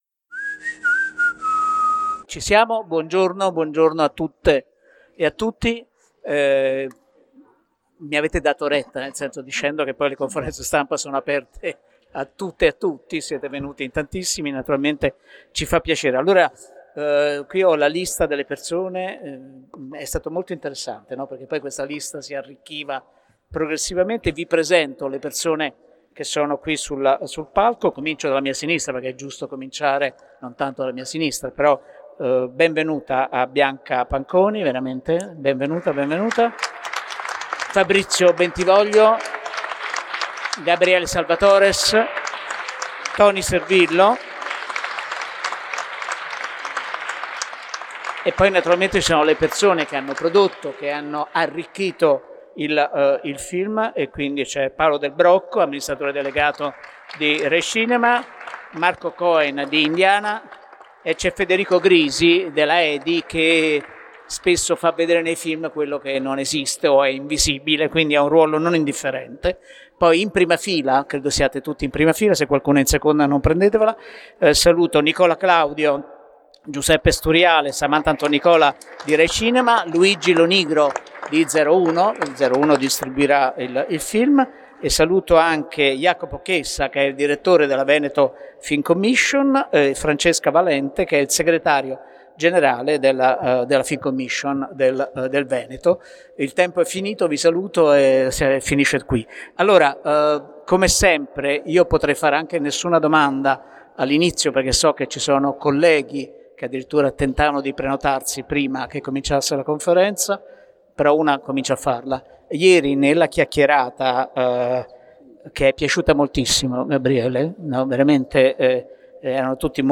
Proiettato al Bif&st di Bari il film, che porta avanti i temi del "doppio", della vecchiaia, della "vita vera" e persino della tecnologia, viene raccontato dai suoi artefici in una gremitissima Conferenza Stampa.